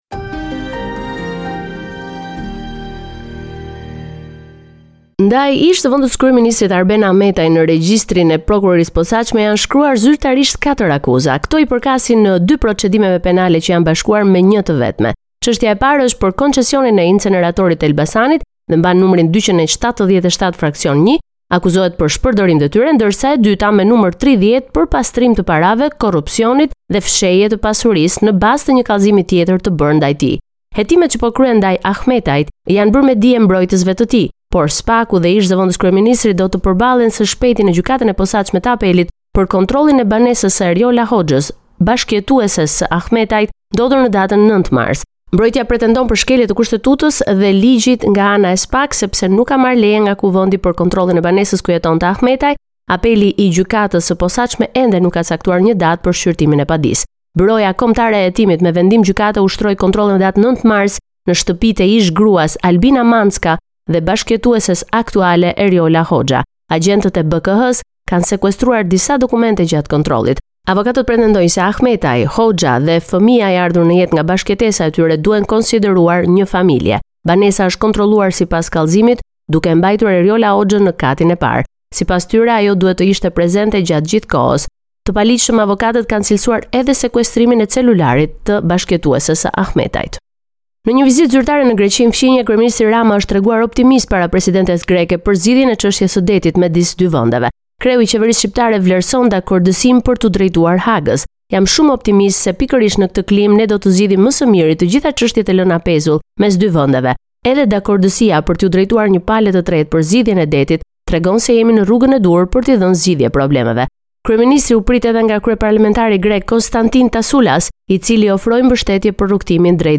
Raporti me të rejat më të fundit nga Shqipëria.